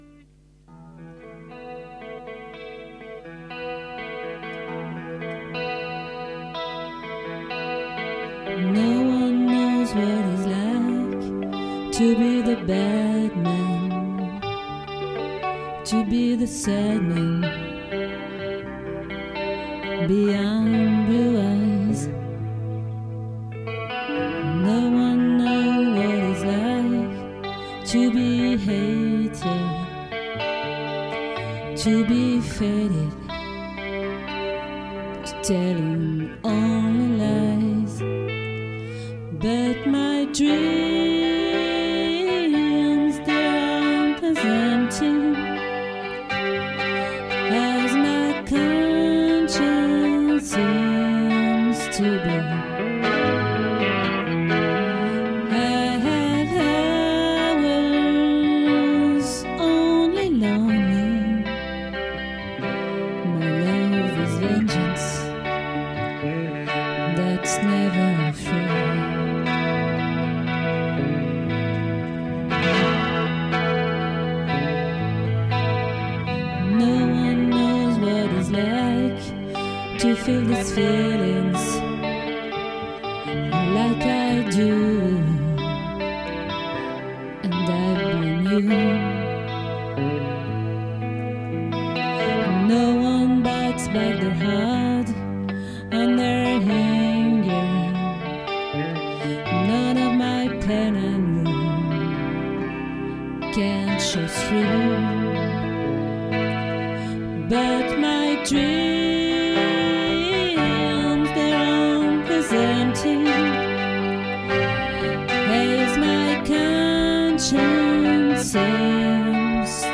🏠 Accueil Repetitions Records_2022_10_05_OLVRE